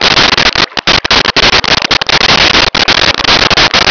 Sfx Amb Lapping Loop
sfx_amb_lapping_loop.wav